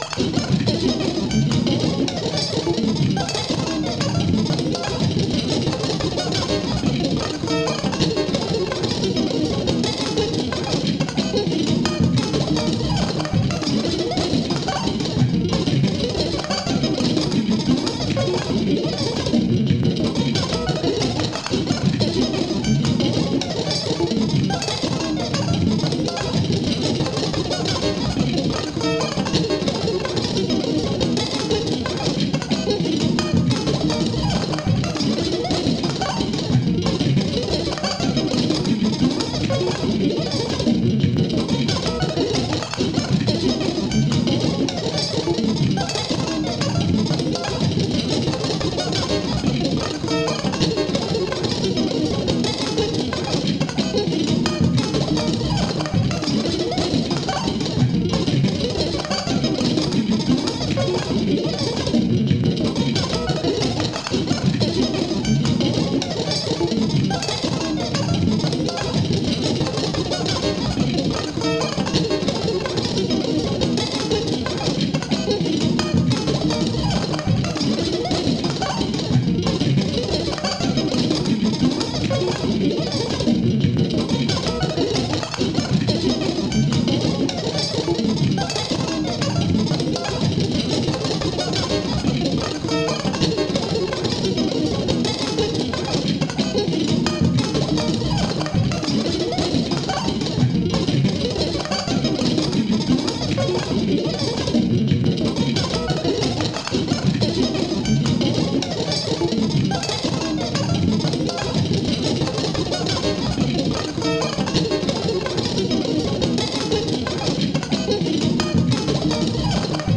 ギターの高速演奏の複雑なオーケストレーション作品で、一聴してギター・アルバム、
しばらく聴き進んで先鋭的テクノの印象を持つ音ですが、聴き終わる頃には、これが古代の儀式的音楽のように思えてきました。
ケチャ、ガムランなどのような、複雑なパルス音楽。